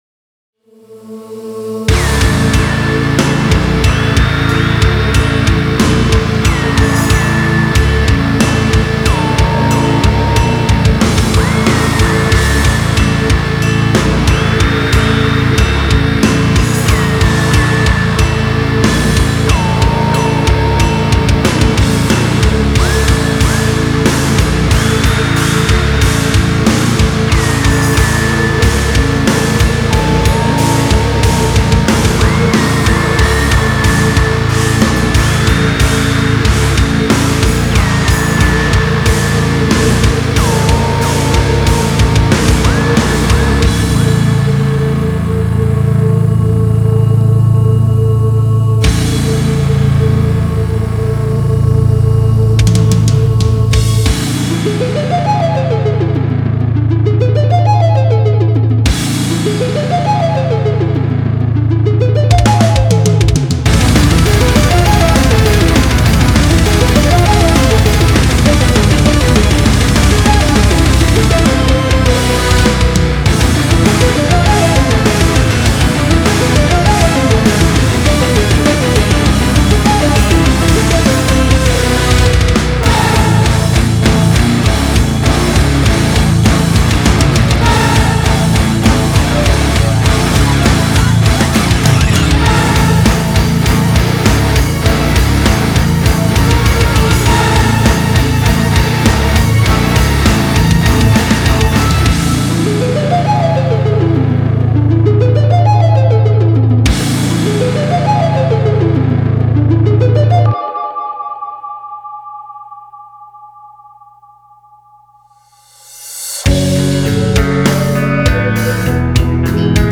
������ - Gibson LP Studio drop A, TSE999 > NickCrow 8505��� - SR5 Rock Bass����������� - ��������, Nord Lead 2, Garage Band Ele...